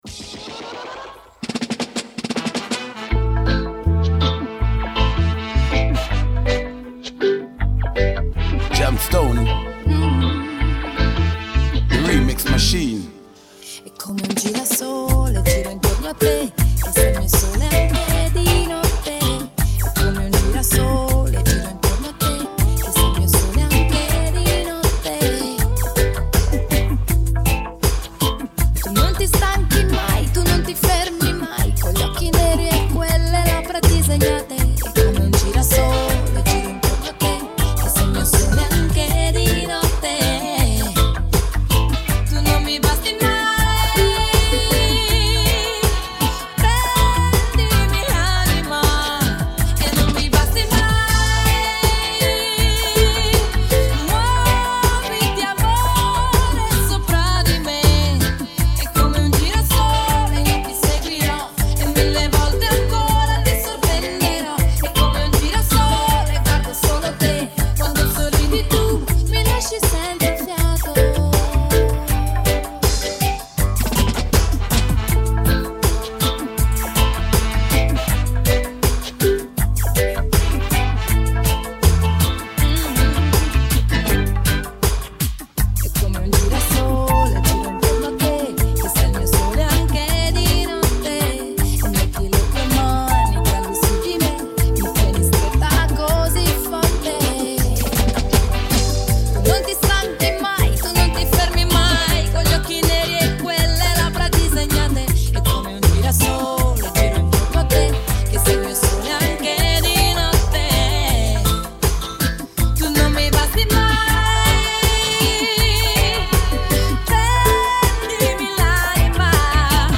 Vocal track
riddim